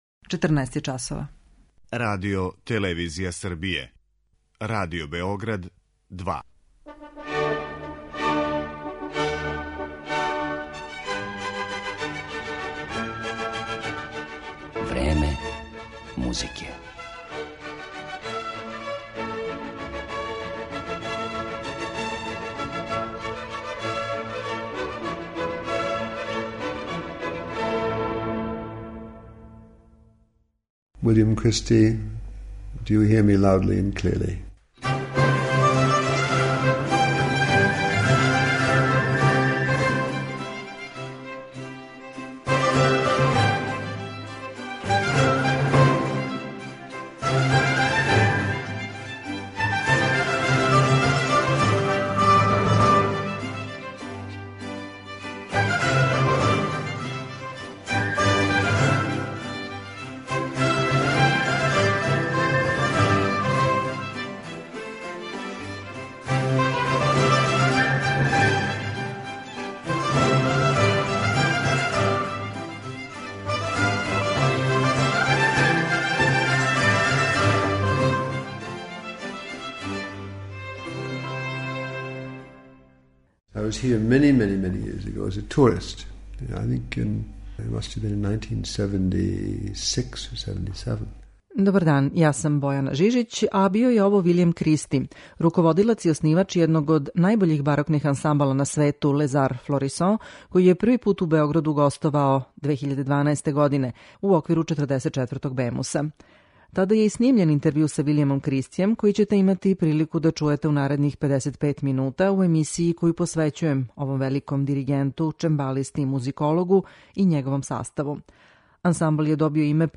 У њој ћете имати прилику да чујете и интервју остварен са Виљемом Кристијем непосредно пред њихов први београдски концерт.